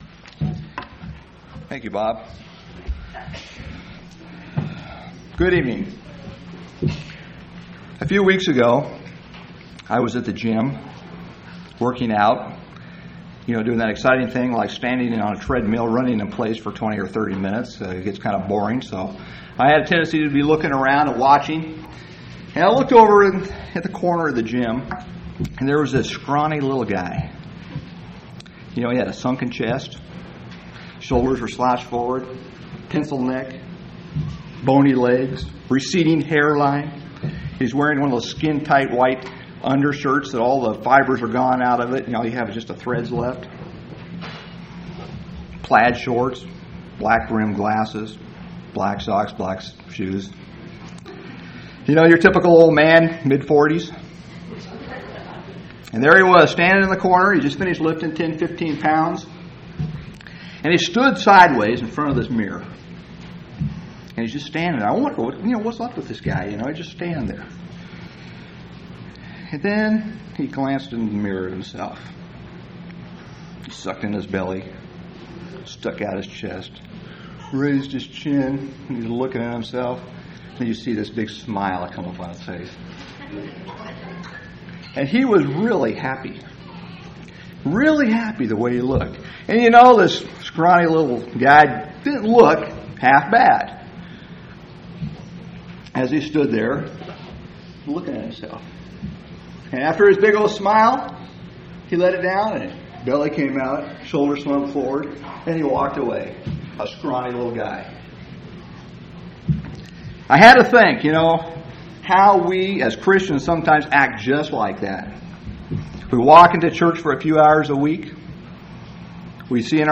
7/12/1998 Location: Phoenix Local Event